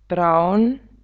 Hneda (640x287)hnědá braun [braun]